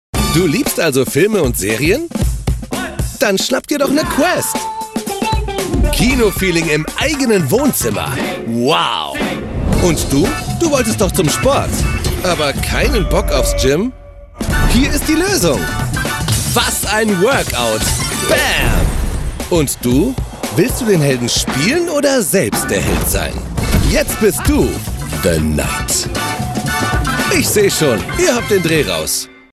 dunkel, sonor, souverän, markant, sehr variabel, hell, fein, zart
Mittel minus (25-45)
Commercial (Werbung), Narrative, Scene